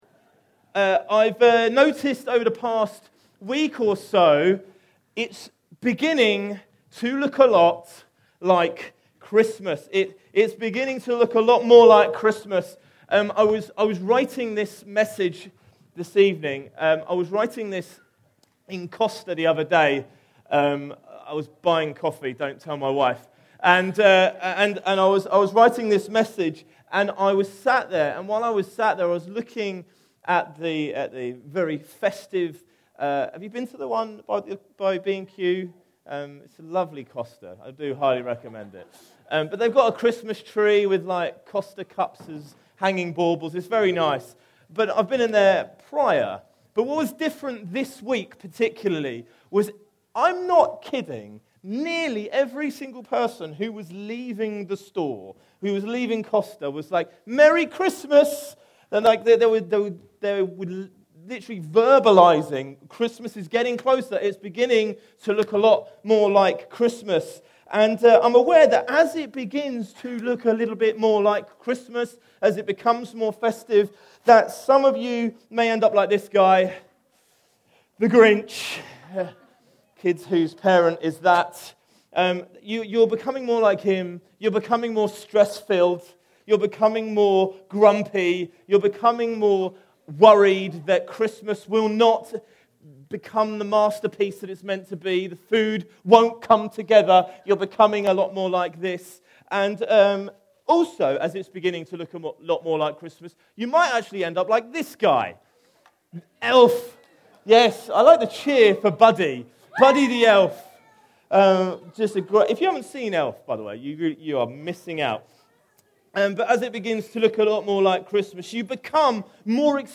Carol Service 2024 (Bognor Regis)